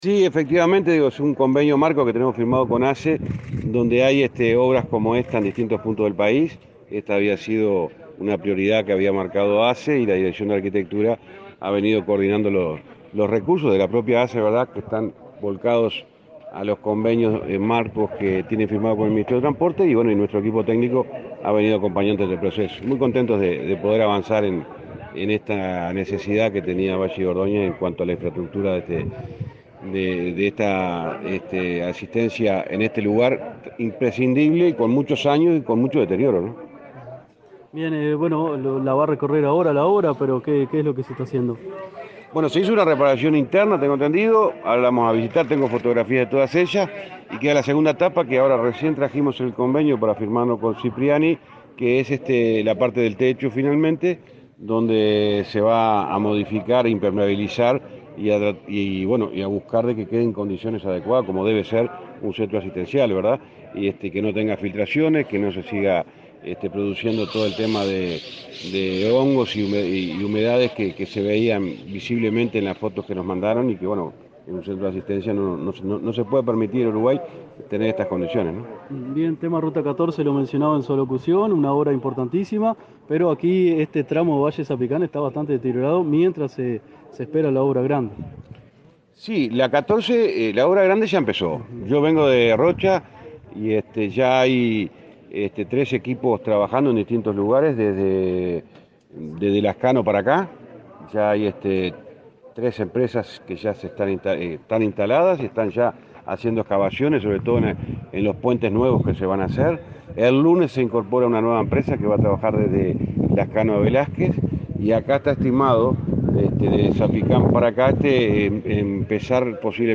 Declaraciones a la prensa del ministro de Transporte